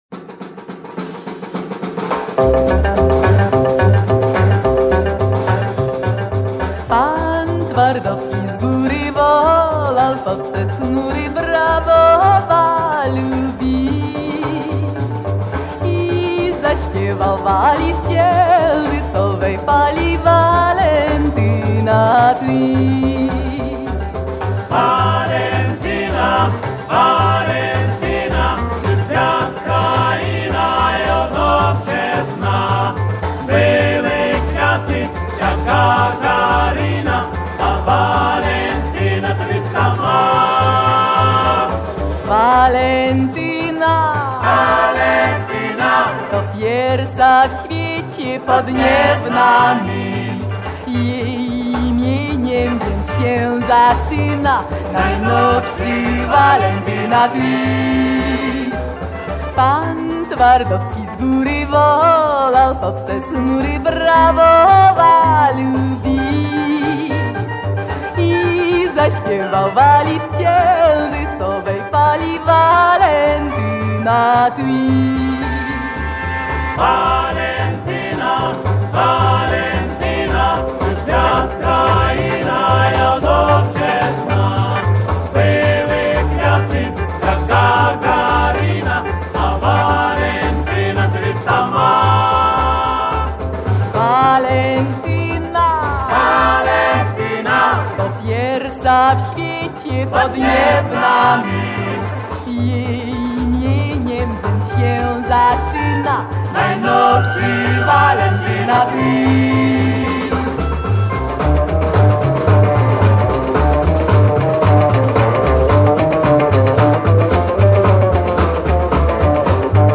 То исполнеие относится больше к стилю "Диско"